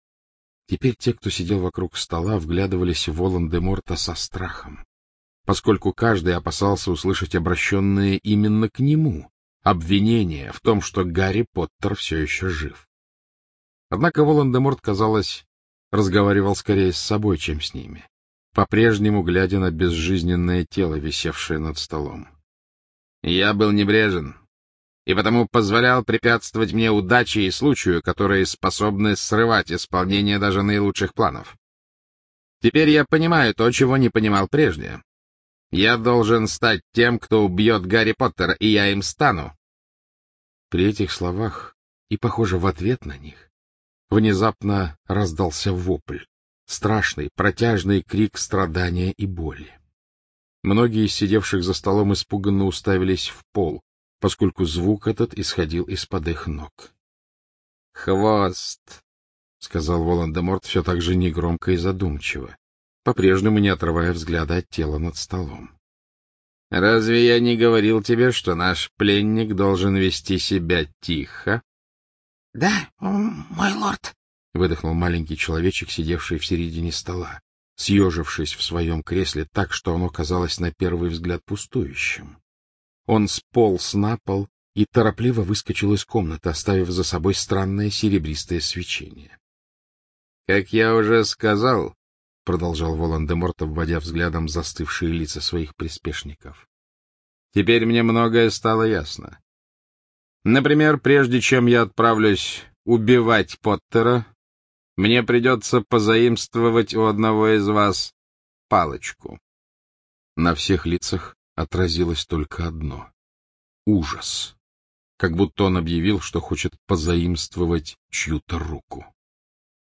Исполнители: Александр Клюквин
Представляем вашему вниманию последнюю аудиокнигу серии Гарри Поттер, писательницы Джоан Кэтлин Роулинг «Гарри Поттер и Дары Смерти».В седьмой, финальной книге Джоан Роулинг «Гарри Поттер и Дары Смерти» Гарри Поттера ждет самое страшное испытание в жизни — смертельная схватка с Волан-де-Мортом.